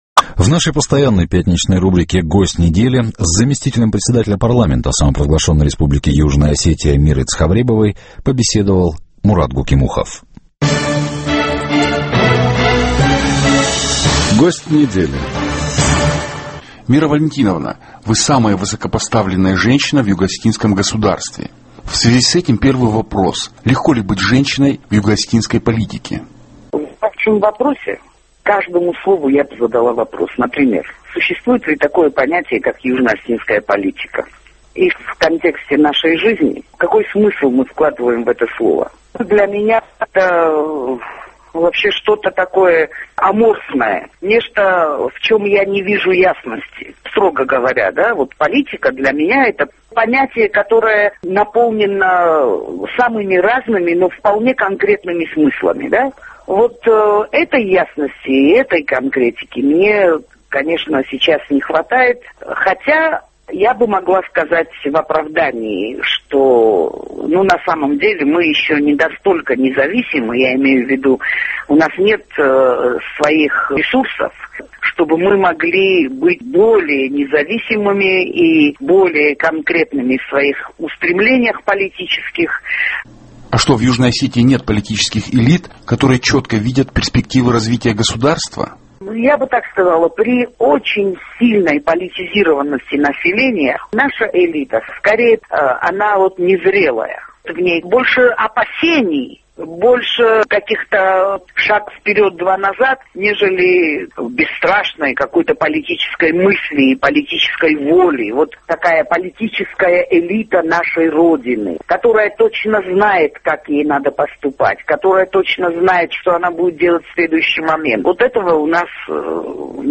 В рубрике «Гость недели» с заместителем председателя парламента де-факто республики Южная Осетия Мирой Цховребовой побеседовал